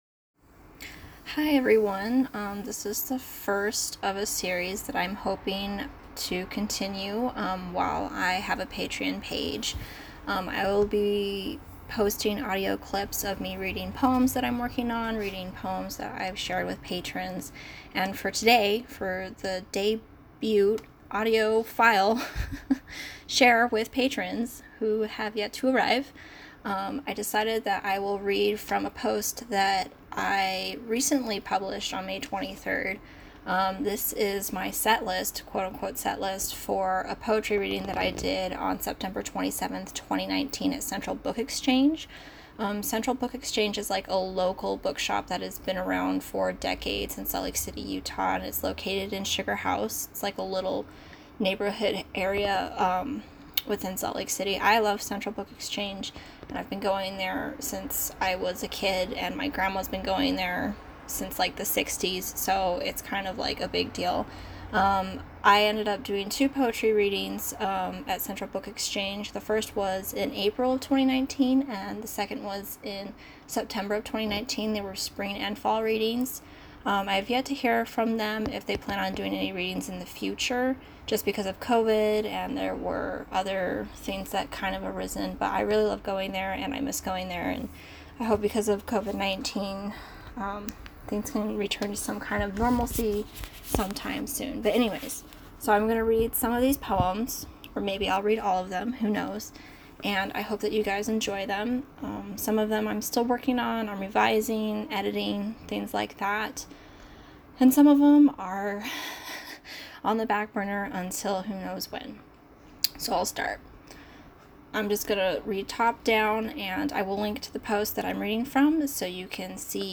Debut Podcast: Poetry Reading and Writing Discussion.